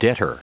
Prononciation du mot debtor en anglais (fichier audio)
Prononciation du mot : debtor